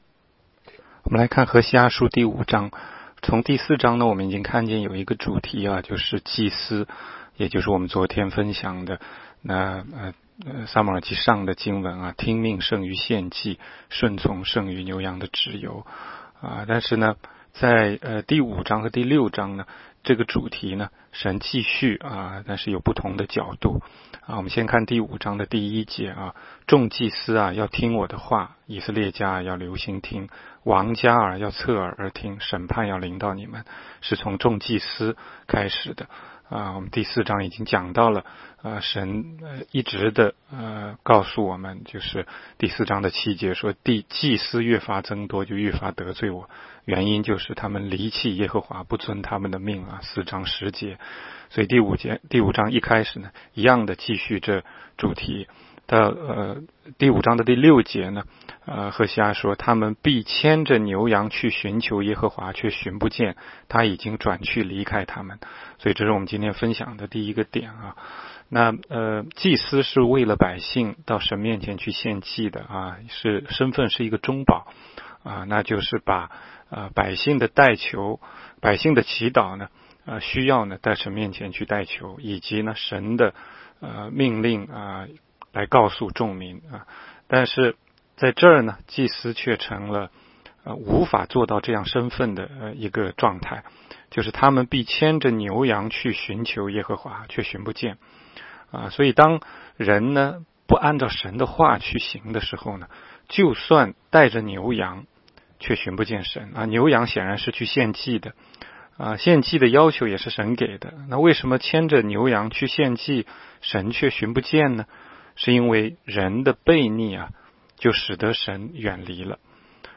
16街讲道录音 - 每日读经 -《何西阿书》5章